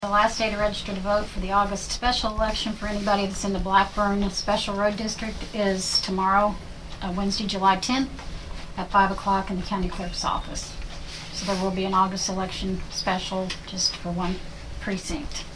Saline County Clerk Debbie Russell gave the details during the meeting of the county commission on Tuesday, July 9.